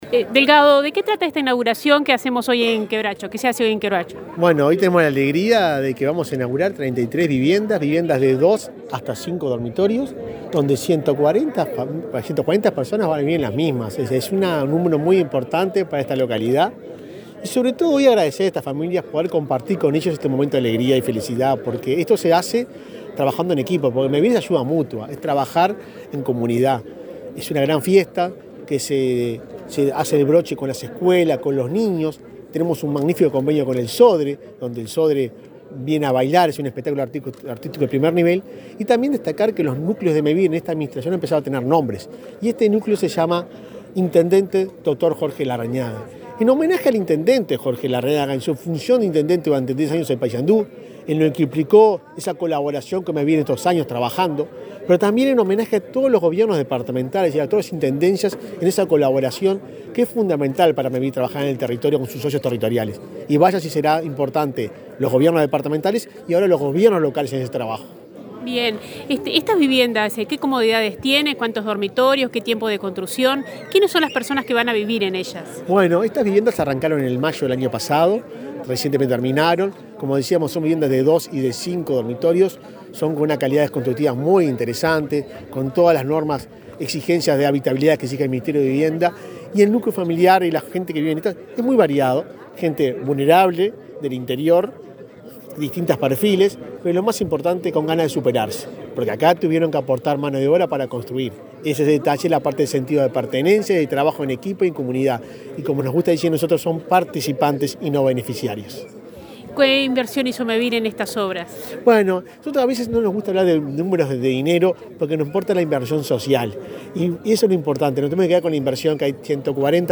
Declaraciones a la prensa del presidente de Mevir, Juan Pablo Delgado
Declaraciones a la prensa del presidente de Mevir, Juan Pablo Delgado 28/10/2021 Compartir Facebook X Copiar enlace WhatsApp LinkedIn Tras la inauguracion de complejo habitacional denominado Doctor Jorge Larrañaga, en Quebracho, Paysandú, este 28 de octubre, Delgado efectuó declaraciones a la prensa.